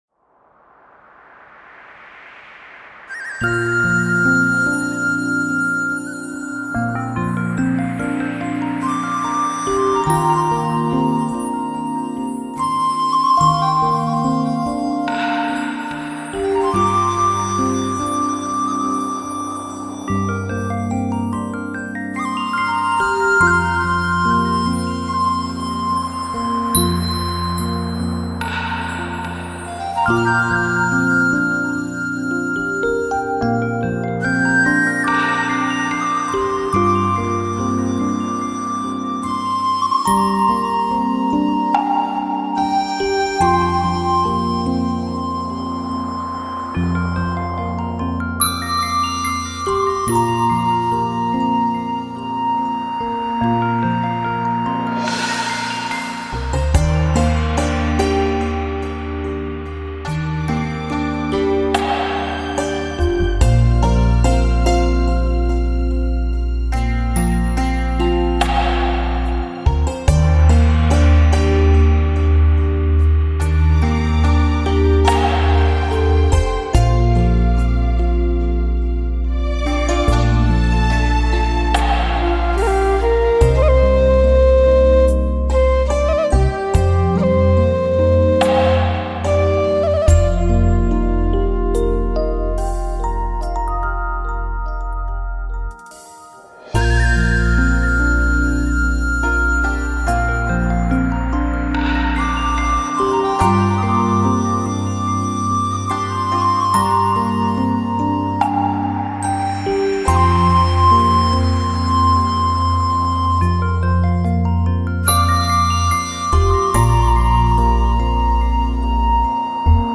曲风柔和悠远，颇有一种琴为伴，笛箫尽显风采之感。
随后清脆的弹拨乐器作为主奏，与远处飘逸的弦乐遥相呼应。